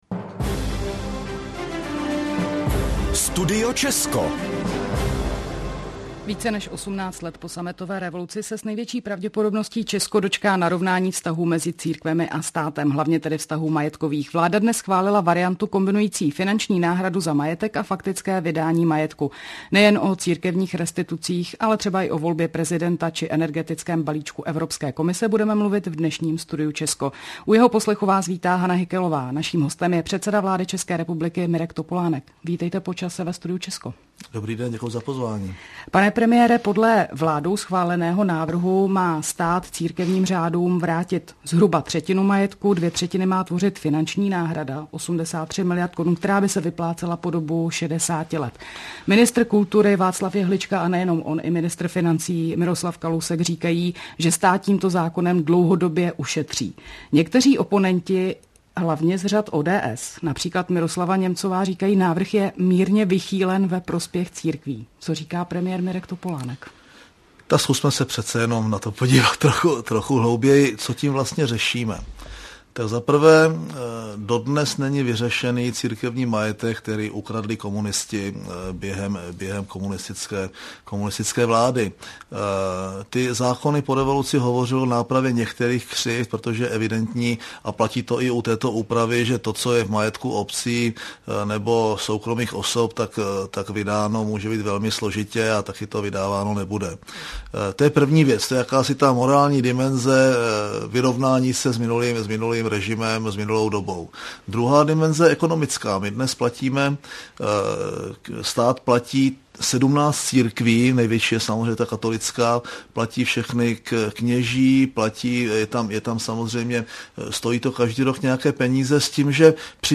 Předseda vlády v rozhovoru pro Český rozhlas hovořil o morálním rozměru restitucí církevního majetku a o nutnosti vyrovnání se s komunistickou minulostí České republiky.
Premier_Mirek_Topolanek_v_rozhovoru_pro_Radio_Cesko.mp3